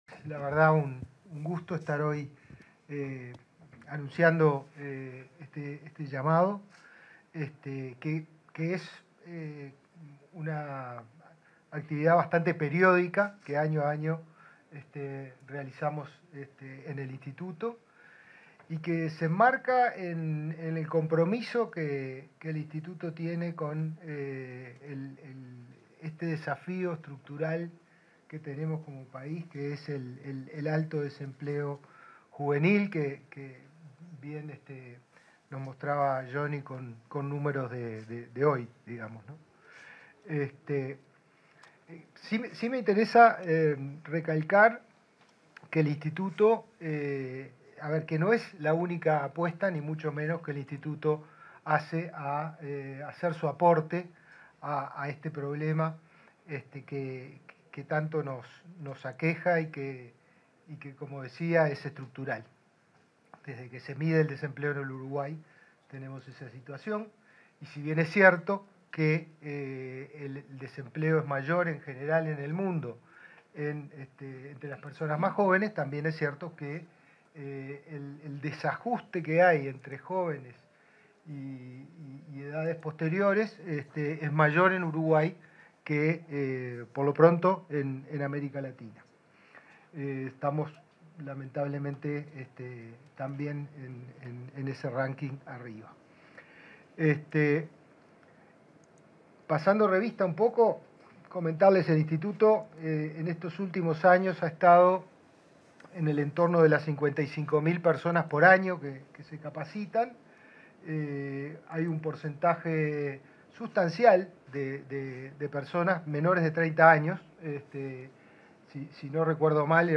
En el marco de la ceremonia de lanzamiento de la convocatoria a proyectos de formación profesional focalizados en jóvenes de 15 a 24 años, se